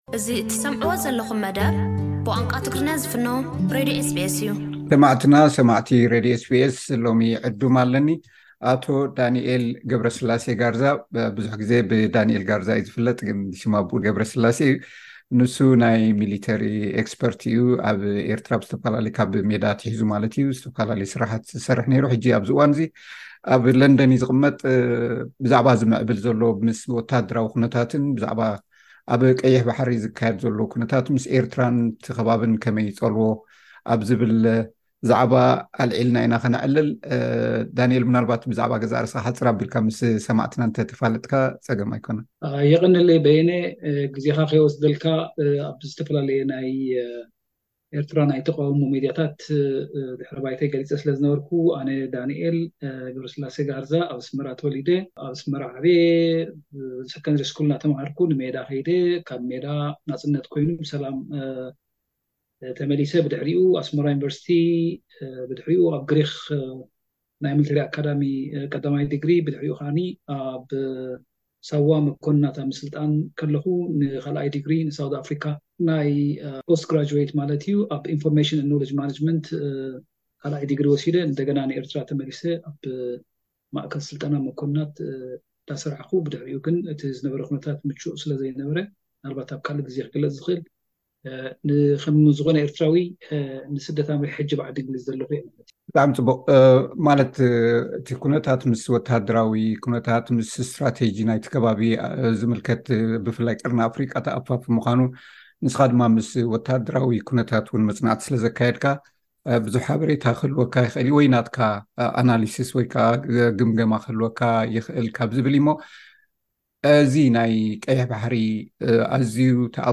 ቀዳማይ ክፋል ቃለ መሕትት።